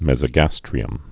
(mĕzə-găstrē-əm, mĕs-)